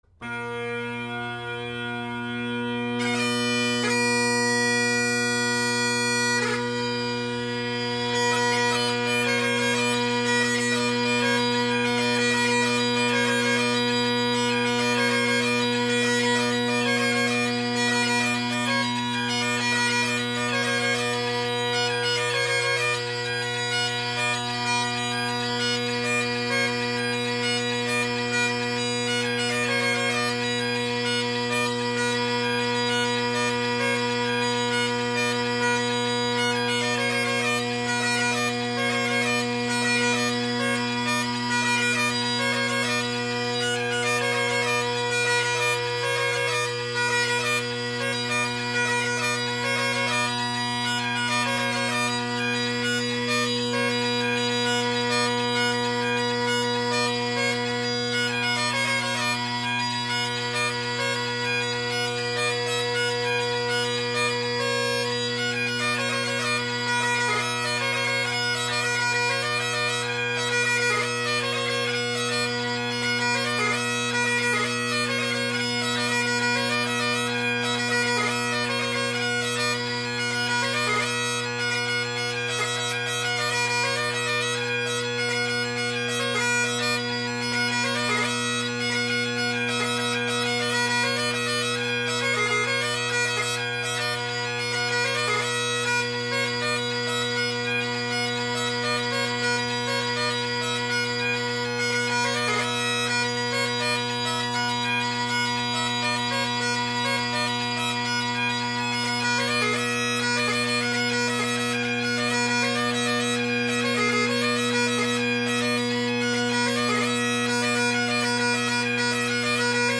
Drone Sounds of the GHB, Great Highland Bagpipe Solo
So the audio that follows is of my band set – the drones + these new drones.
Sorry about the sharp F and flat high A, at times, and occasional cut out.
The mic was placed behind me since we’re listening to the drones here (I’m a drone guy, what can I say?).
The bass drone may be a tad quiet, of course Redwood tenor reeds offer a bold tone without the harshness of other bold tenor reeds, so it may be relative.